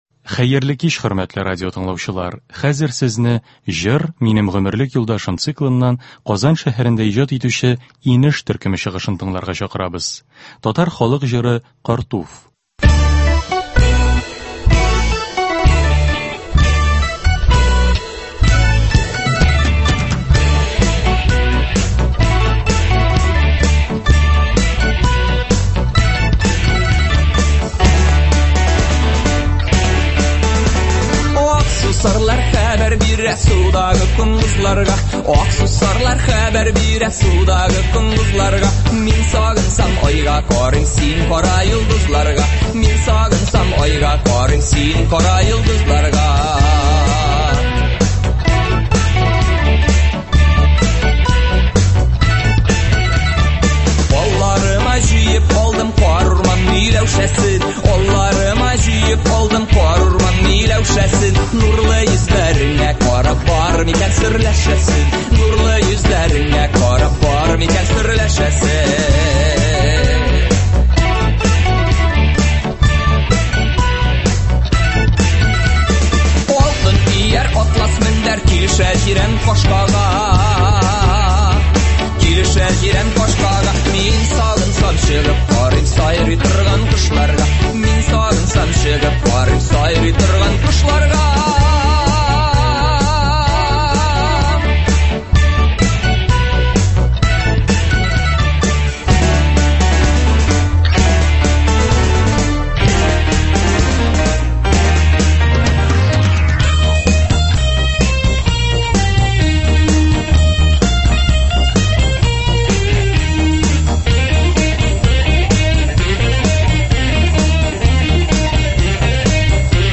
Концерт (11.01.21)